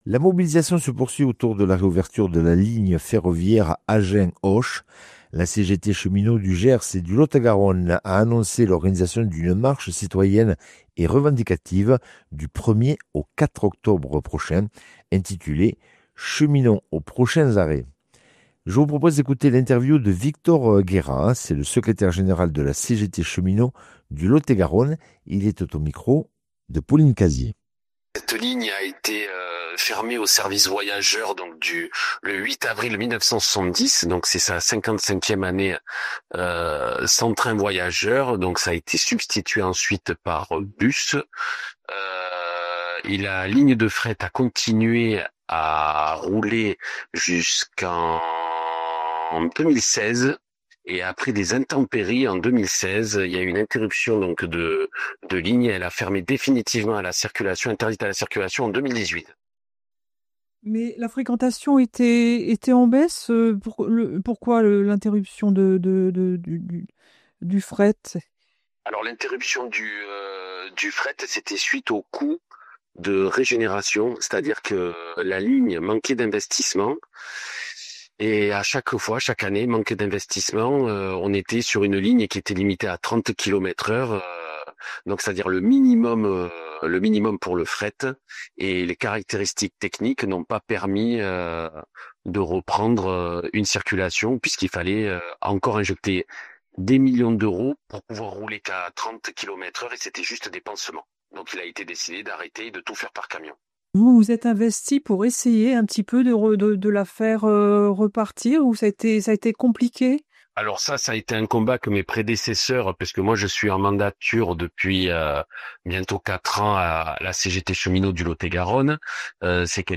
Interview et reportage du 17 sept.